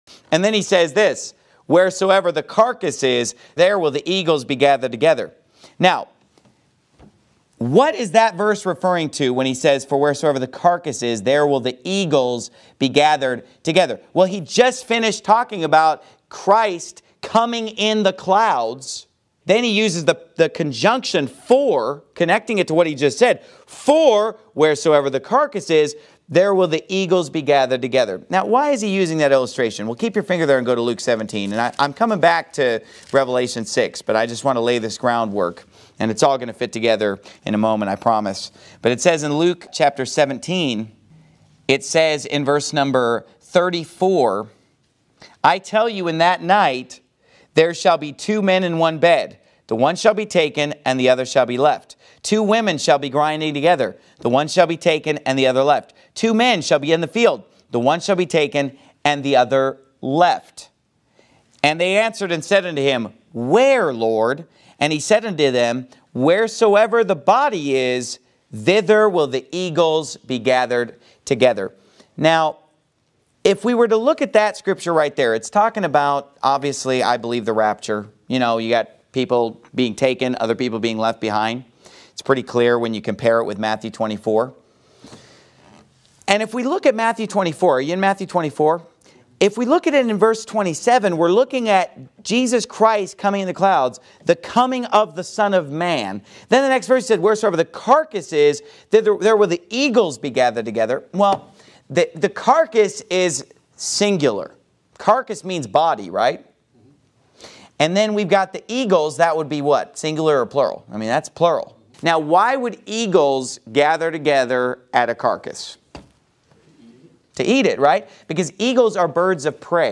Every NIFB Sermon and More